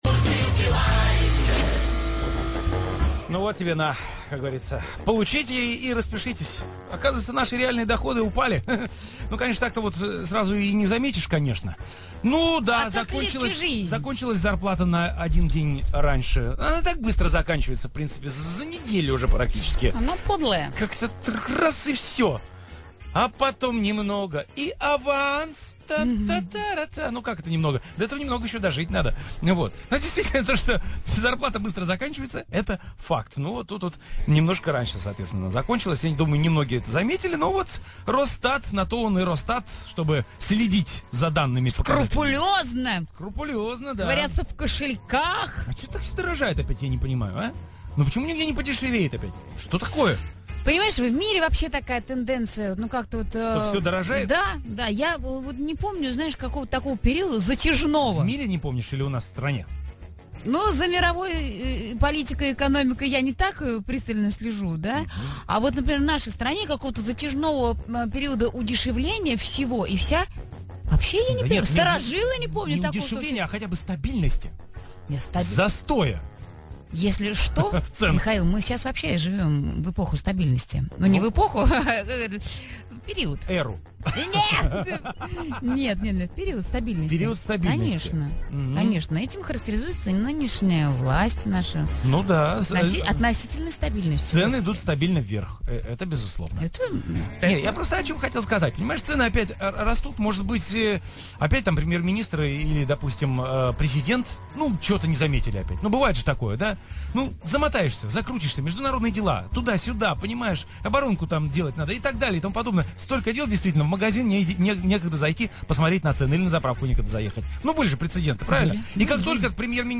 Утреннее шоу "Мурзилки Live" на "Авторадио" Москва. Запись эфира.
Запись отрывка самого титулованного столичного утреннего шоу.